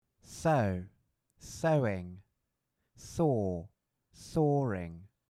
🧵 sewing – /ˈsəʊ(w)ɪŋ/        🪚 sawing – /ˈsɔːrɪŋ/